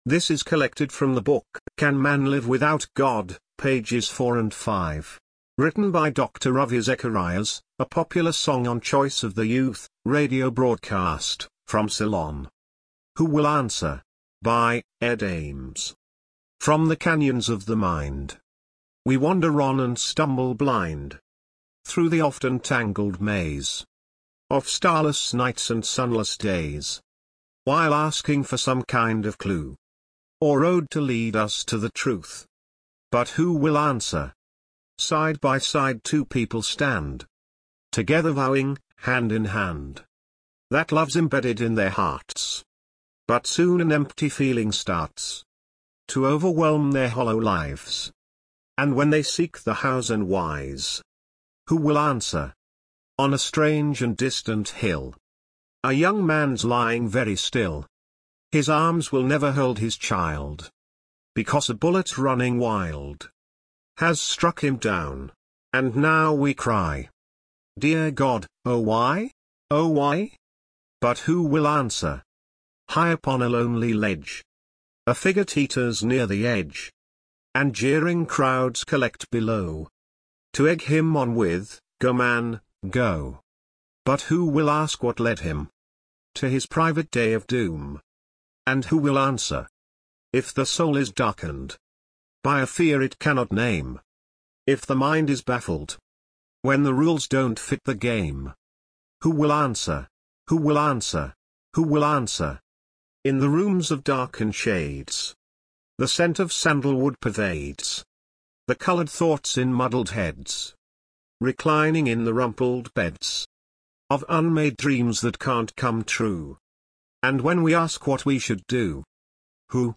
Audio Version of this article
Many Thanks to Amazon Polly